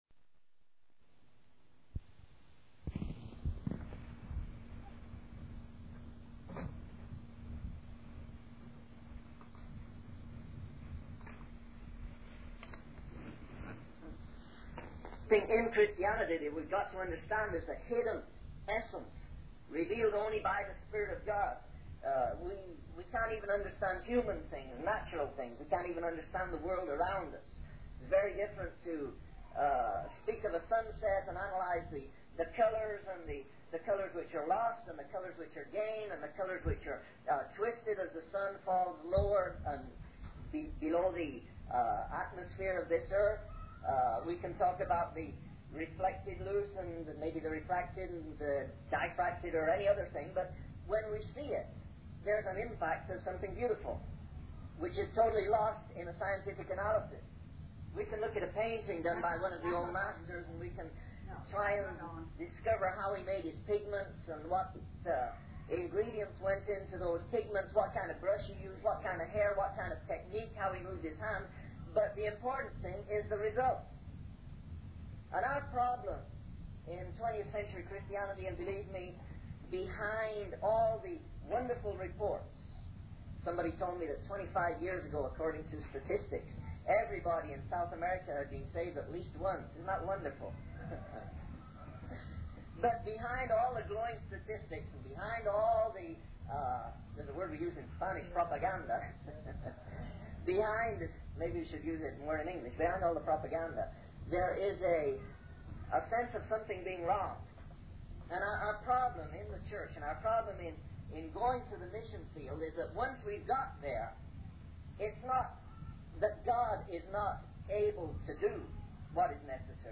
In this sermon, the speaker highlights a sense of something being wrong behind the glowing statistics and propaganda in the Church. The problem lies in the believers' inability to do what is necessary, despite God's power.